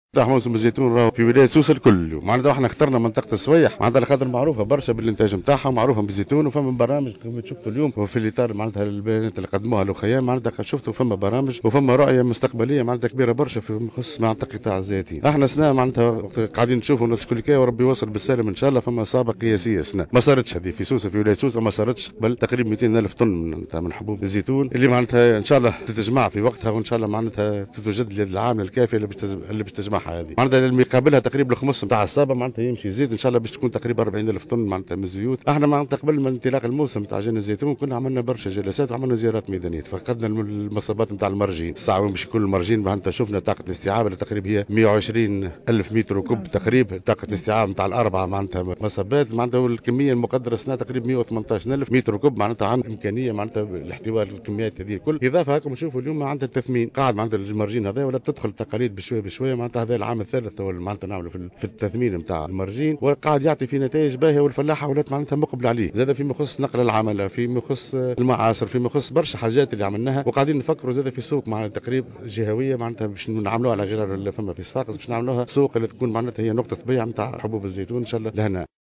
ومن المتوقع أن تصل صابة زيت الزيتون خلال الموسم 2019/ 2020 وفق ما صرّح به والي الجهة للجوهرة "أف أم"، إلى 200 ألف طن زيتون أي ما يعادل 40 الف طن من الزيت ، وقد تم تخصيص 96 معصرة للغرض. كما أضاف "الشليوي" أنه سيتم إحداث سوق جهوية لبيع الزيتون على غرار سوق قرمدة بصفاقس.